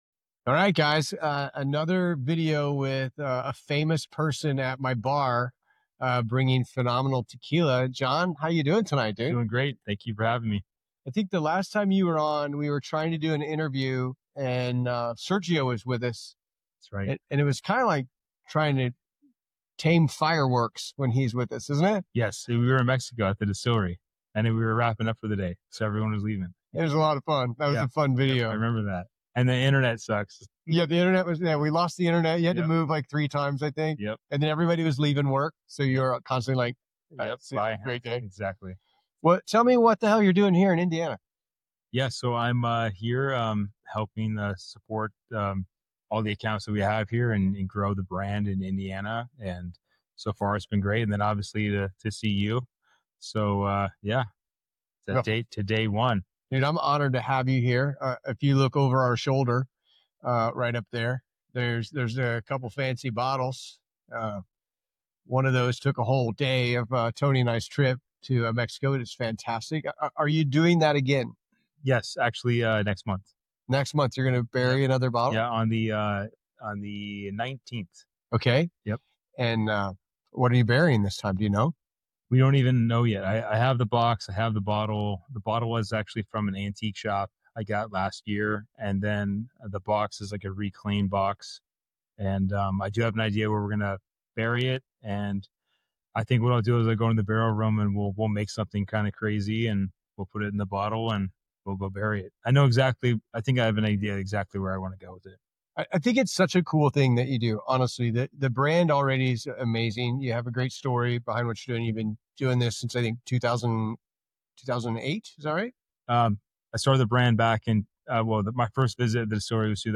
tequila interview